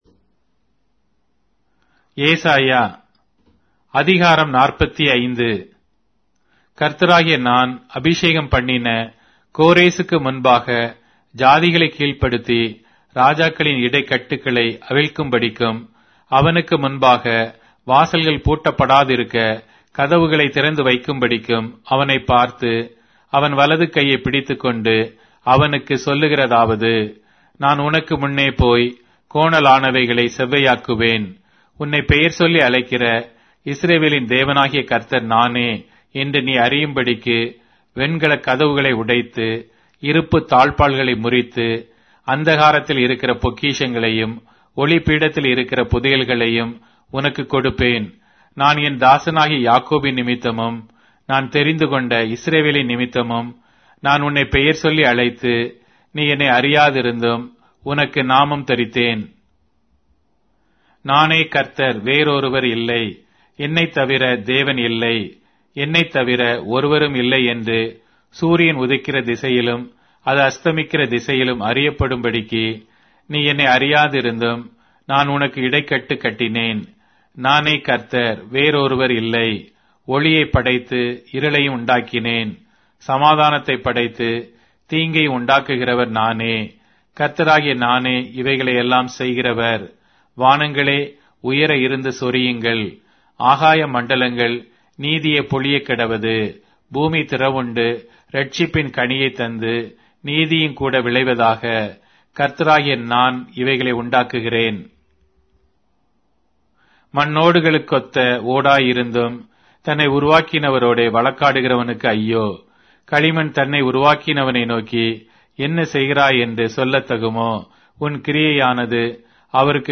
Tamil Audio Bible - Isaiah 18 in Orv bible version